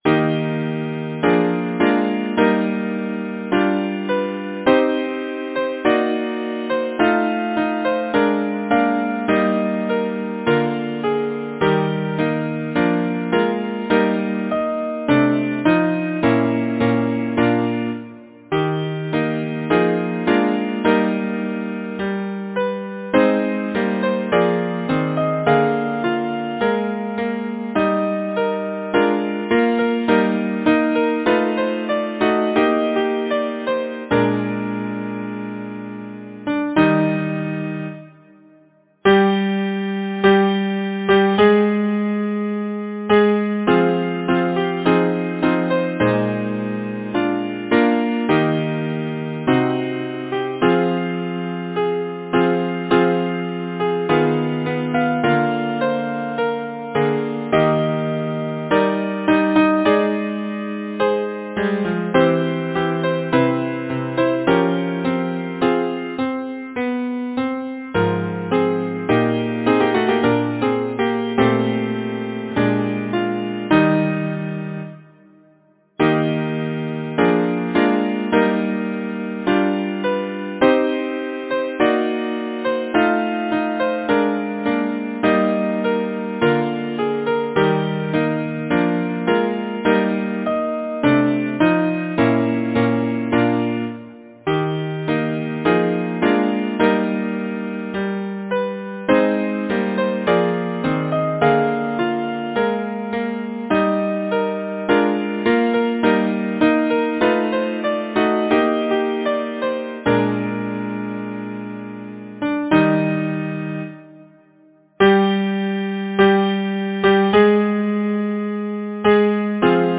Title: Now daylight fades Composer: John Ernest Richard de Valmency Lyricist: Alicia Julia Foot Number of voices: 4vv Voicing: SATB Genre: Secular, Partsong
Language: English Instruments: A cappella